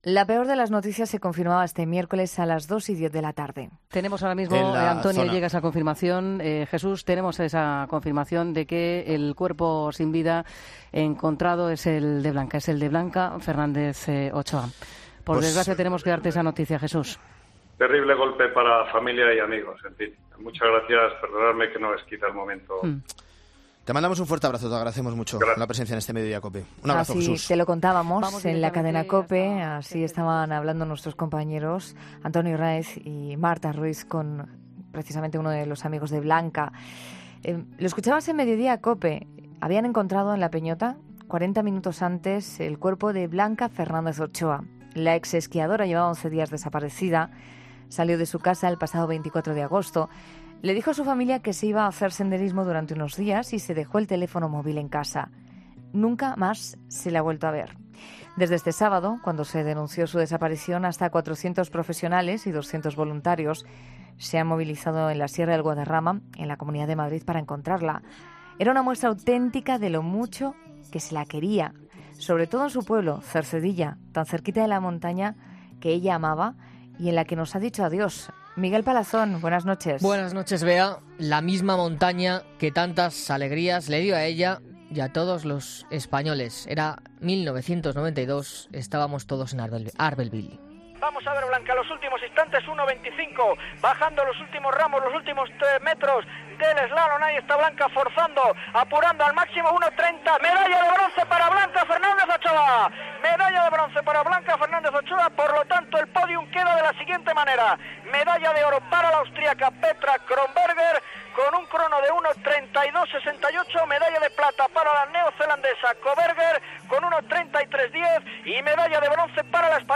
La atleta ha atendido la llamada de 'La Noche de COPE' para hablar sobre la trágica noticia del fallecimiento de Blanca Fernández Ochoa